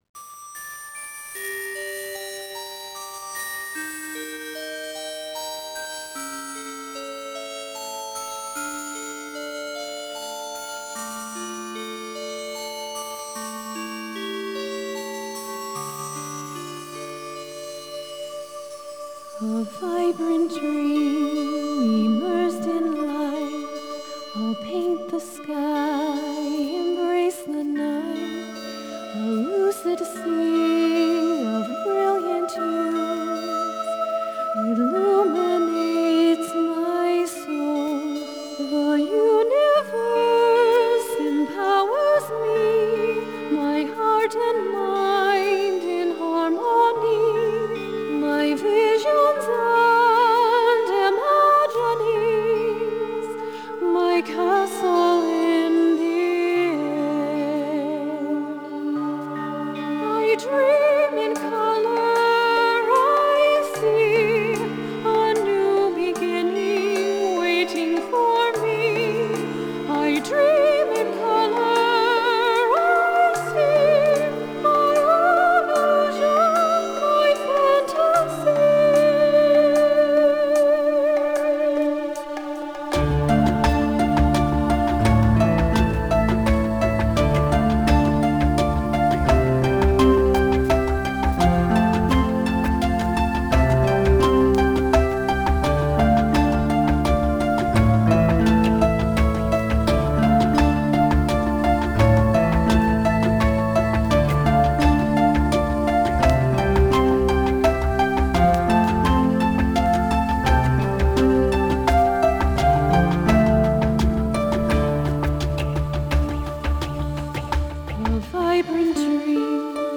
Genre: Electronic, Classical Crossover, Singer-SAongwriter